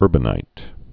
(ûrbə-nīt)